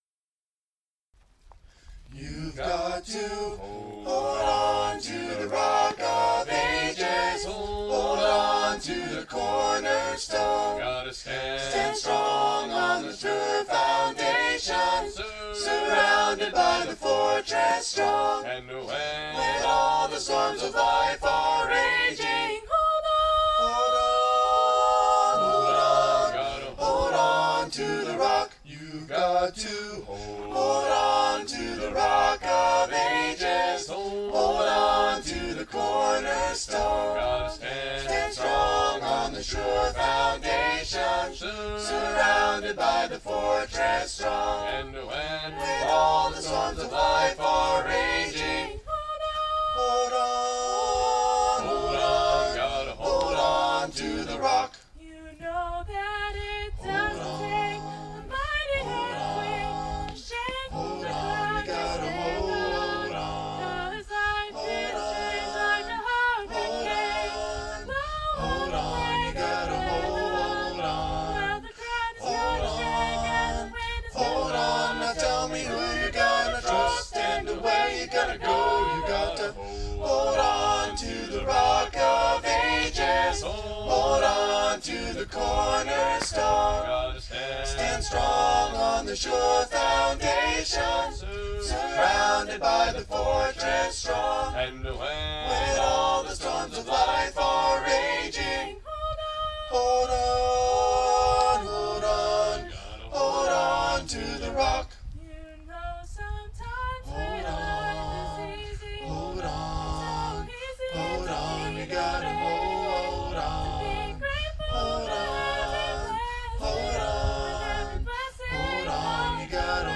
Tuesday Campfire
Tuesday-Campfire-2022.mp3